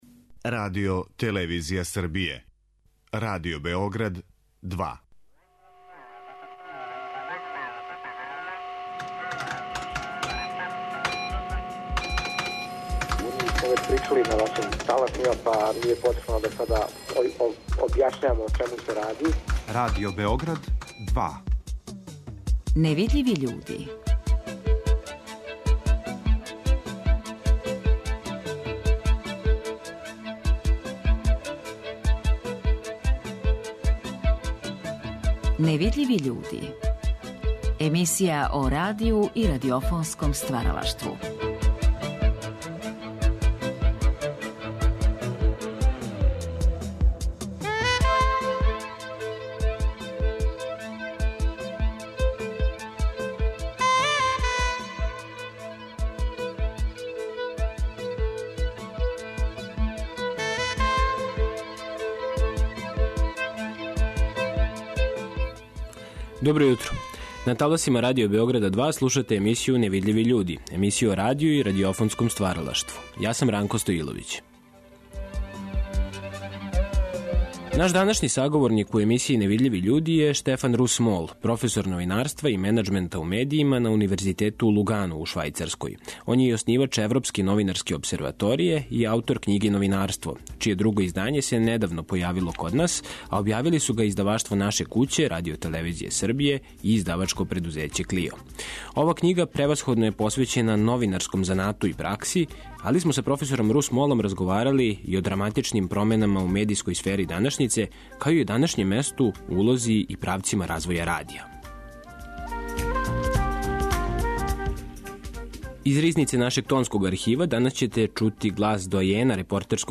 Ови разговори вођени су у оквиру циклуса "Гост Другог програма".